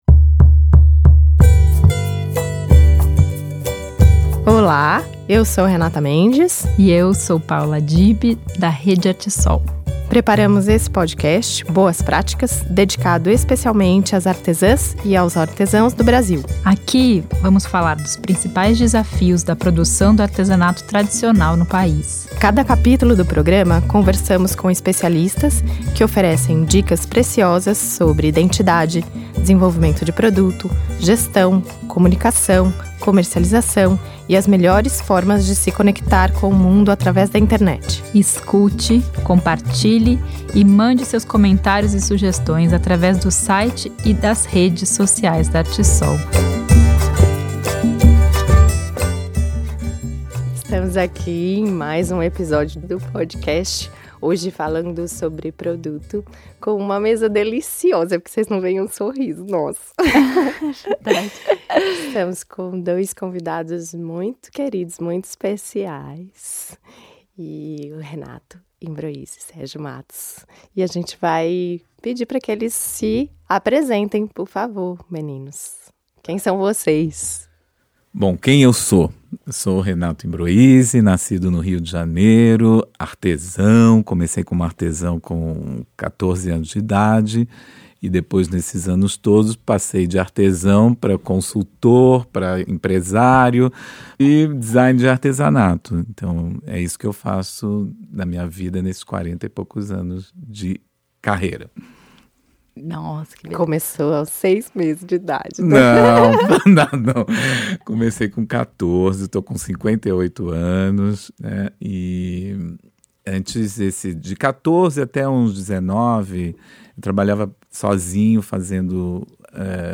O design deve contar uma história que valoriza a cultura por trás da técnica artesanal. Nesse bate papo os designers